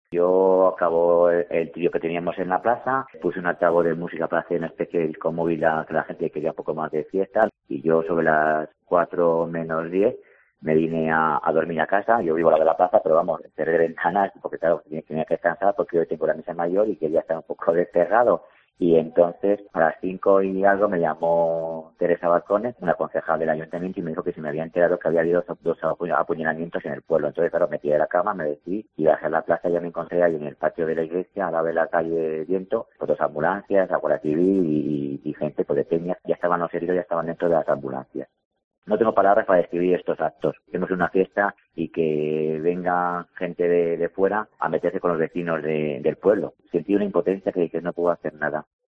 El alcalde de Salmerón, Óscar Balcones, ha contado en Mediodía COPE Guadalajara cómo la noticia del suceso le hizo saltar de la cama.